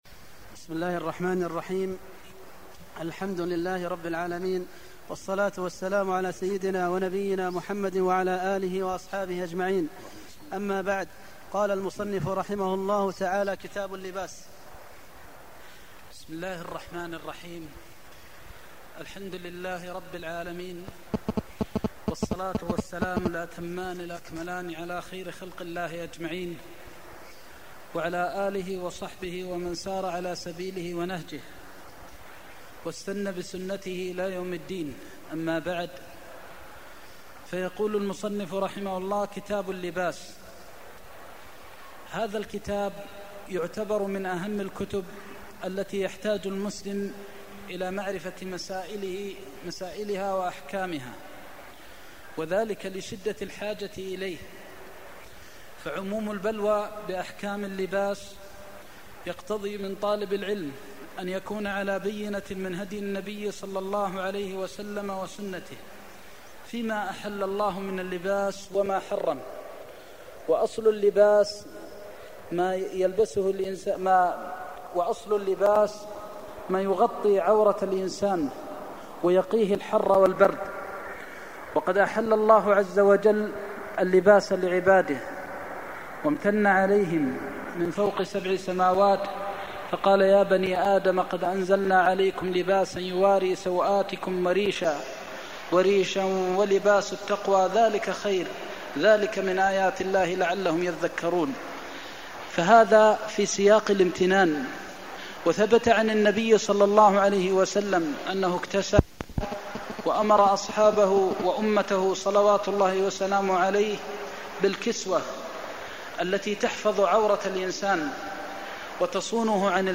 المكان: المسجد النبوي الشيخ: فضيلة الشيخ د. محمد بن محمد المختار فضيلة الشيخ د. محمد بن محمد المختار اللباس (1)